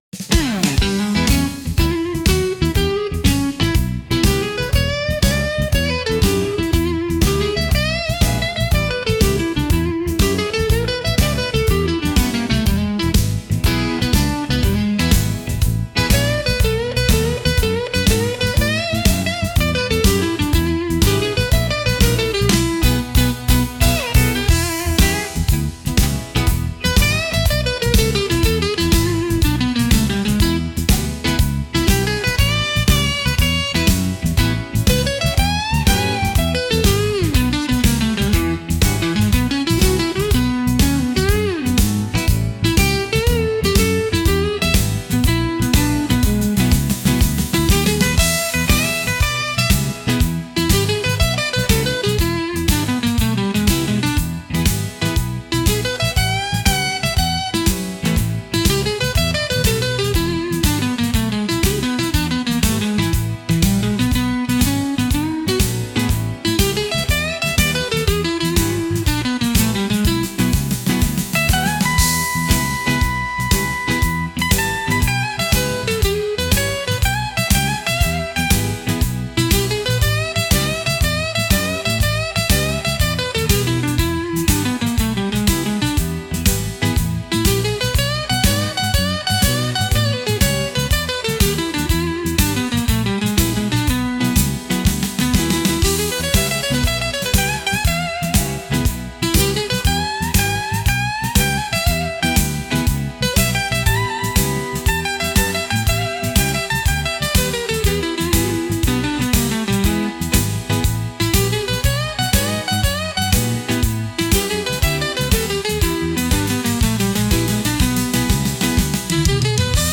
Instrumental -Swamp Dust Rising 3.43